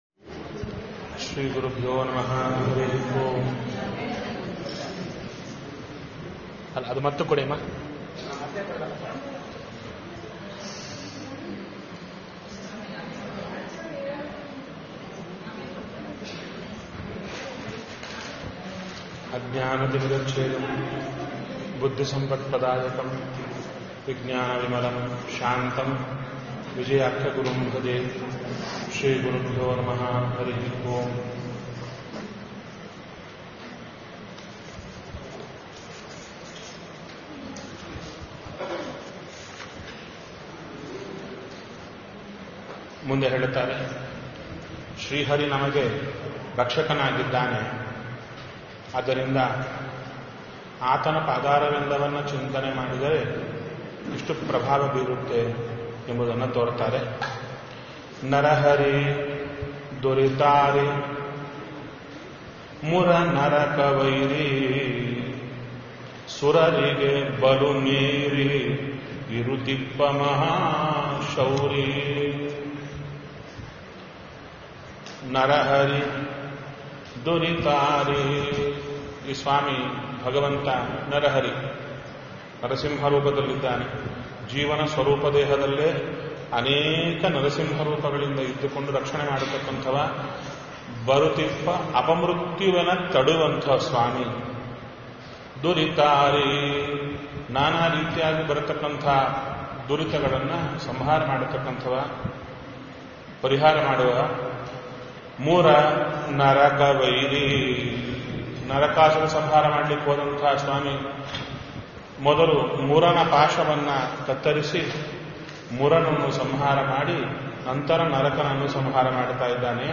Spiritual discourses, audio messages, events, and downloadable resources from Kurnool Achars Chintana.